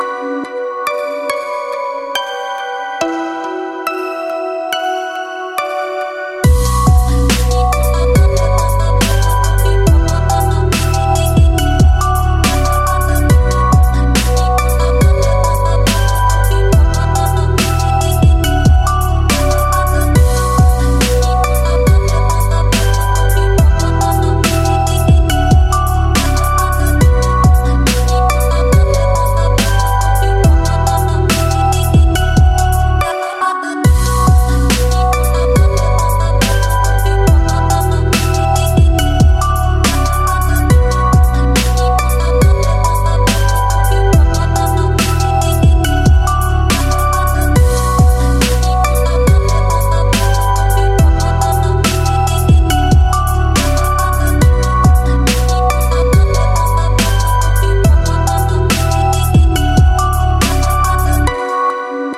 • Качество: 320, Stereo
красивые
спокойные
без слов
инструментальные
романтичные
Лирическая музыка